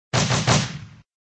SShootBurst.ogg